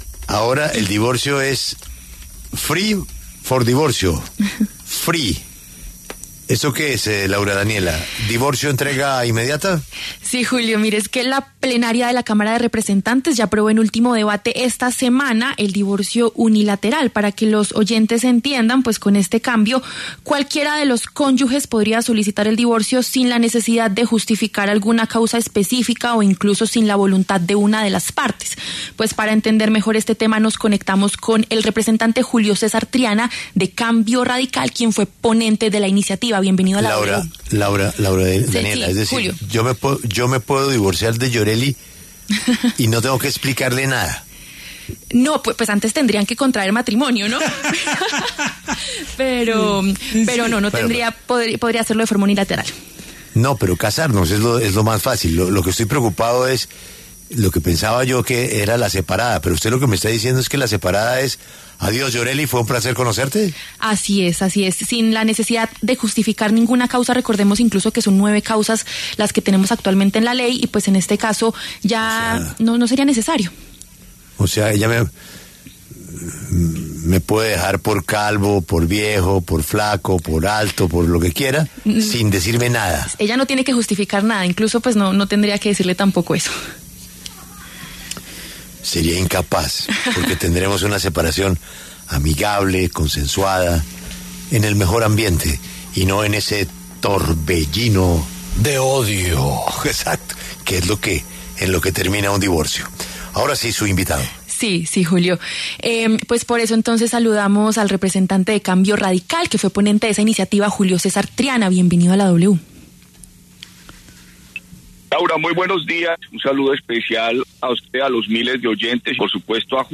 Los representantes Julio César Triana, de Cambio Radical, y Juan Manuel Cortés, de LIGA, debatieron en los micrófonos de La W.